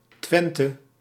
Twente (Dutch: Twente [ˈtʋɛntə]
Nl-Twente.ogg.mp3